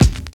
44 KICK 3.wav